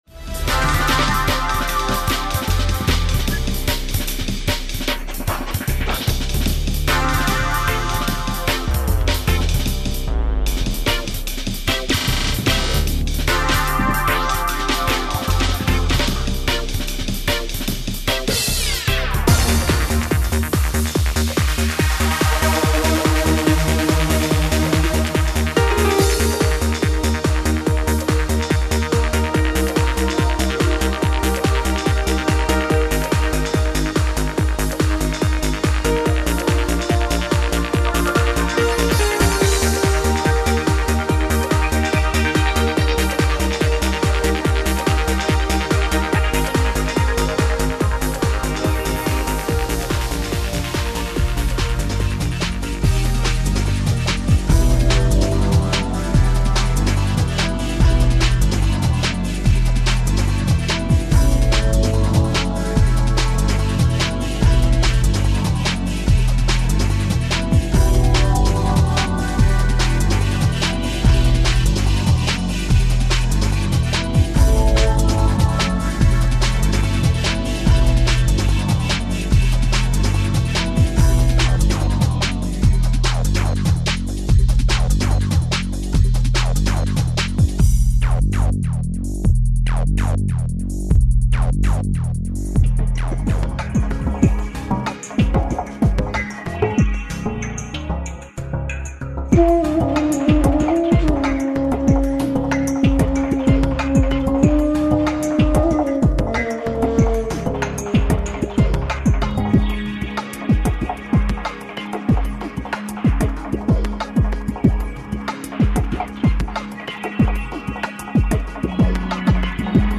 ELECTRONIC PRODUCTION DEMO 01